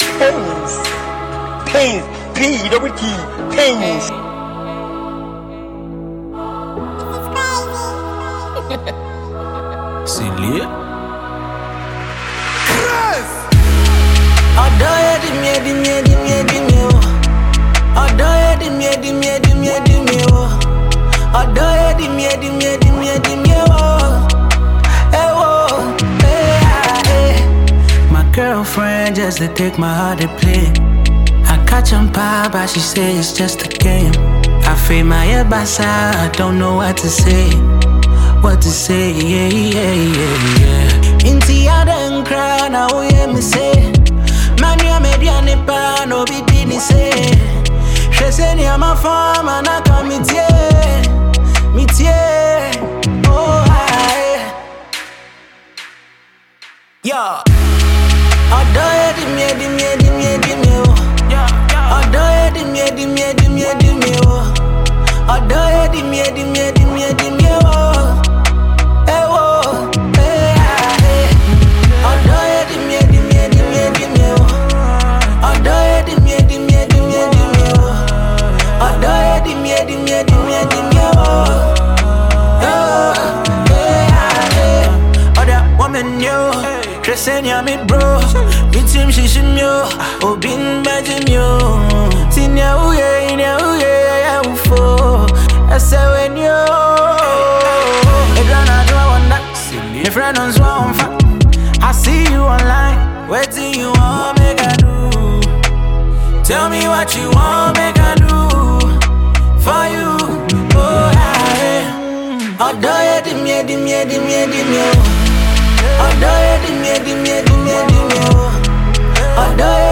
drill